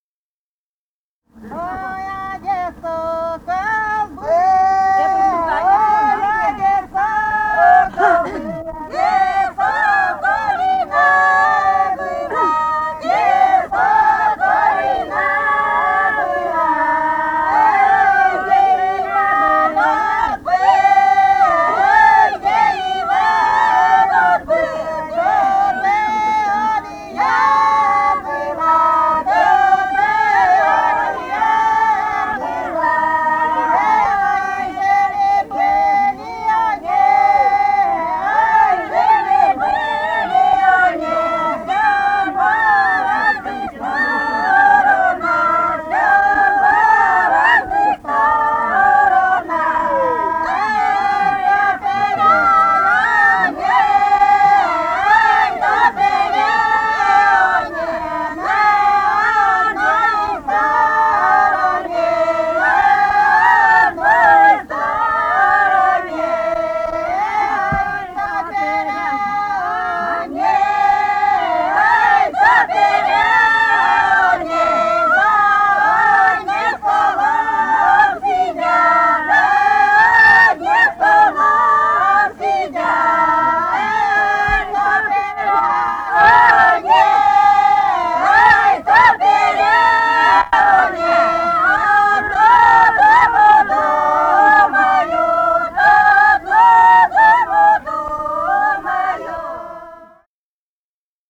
полевые материалы
«Ой, а где сокол был» (свадебная). Пел Хор народных исполнителей. Костромская область, 1964 г. И0790-09